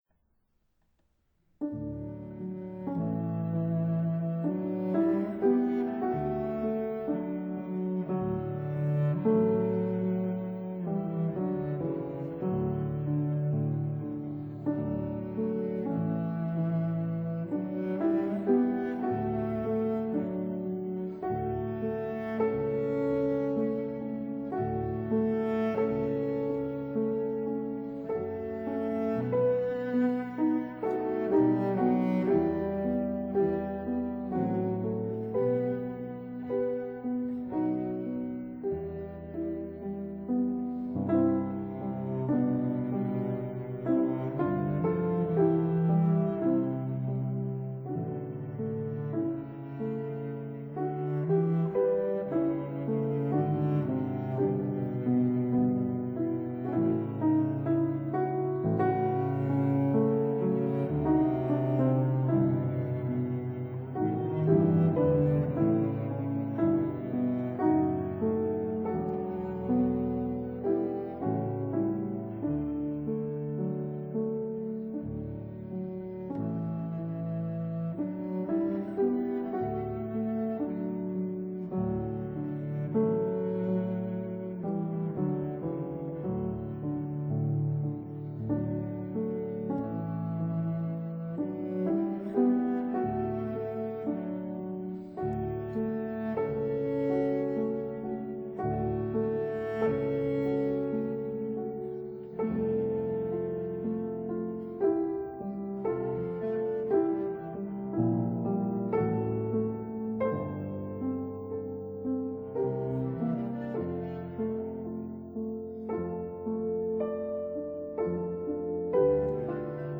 Cello
Fortepiano
(Period Instruments)